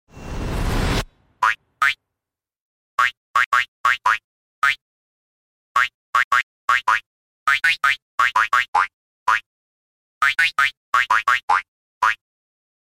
Zwischen Hoppeln und Herzschmerz: Welcher Song ist das?